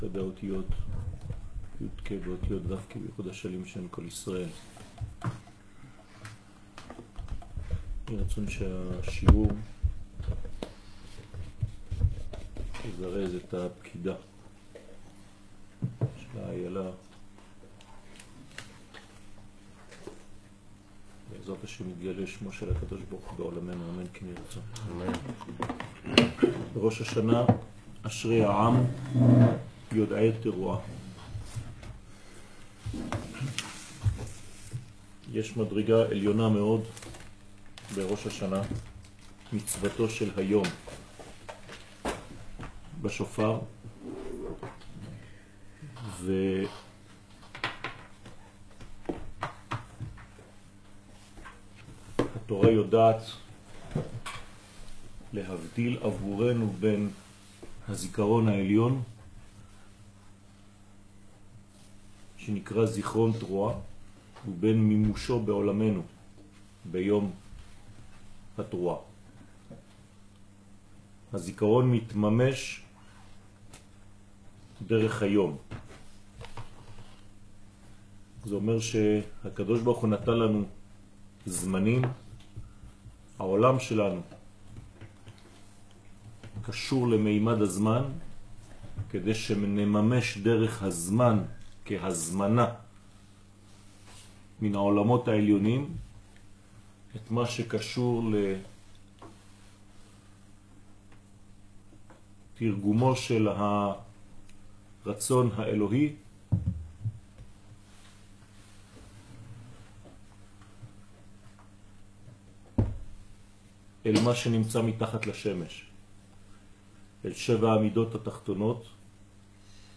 שיעור לכבוד ראש השנה תשע”ט
שיעור-לכבוד-ראש-השנה-תשע-ט.m4a